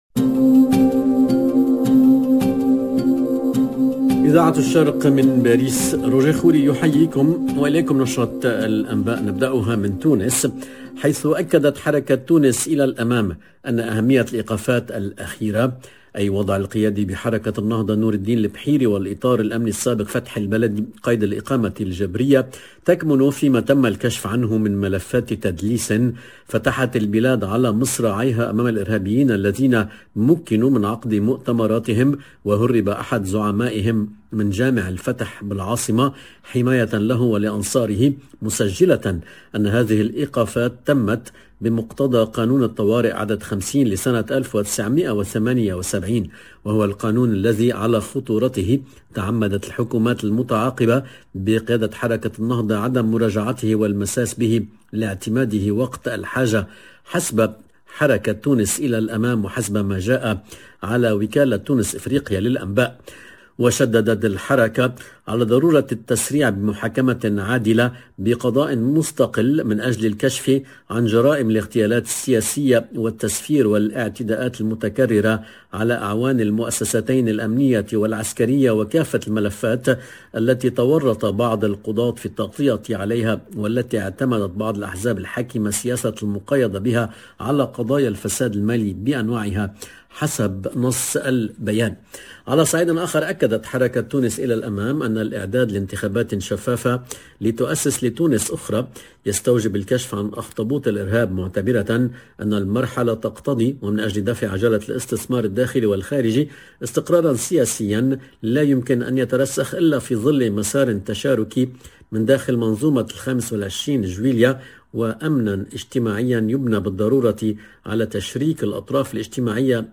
LE JOURNAL DU SOIR EN LANGUE ARABE DU 7/01/22